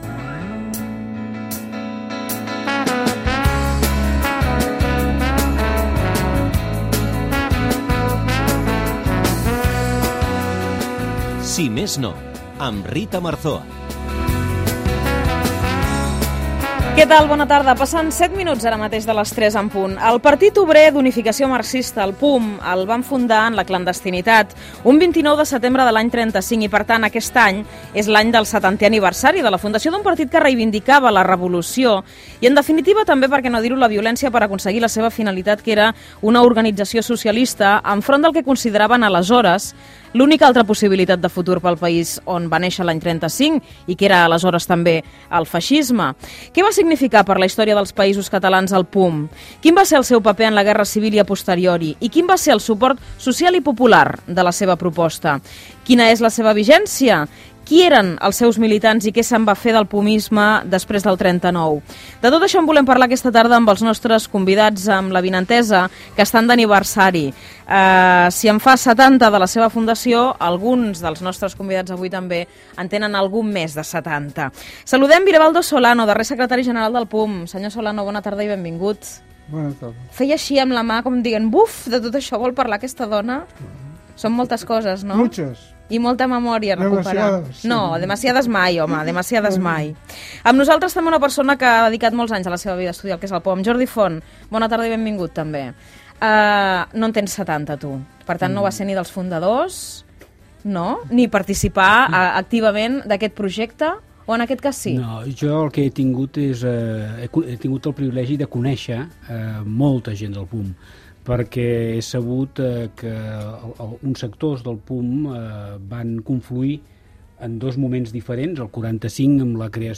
Careta del programa, hora, apunt històric del Partit Obrer d'Unificació Marxista (POUM), reflexions, explicacions i opinions
Publicitat, promoció d'Àgora de TV3 Gènere radiofònic Divulgació Anunciant Hipo Gegant, Marc Franc, L'Auditori, El Corte Inglés, Teatre Tivoli.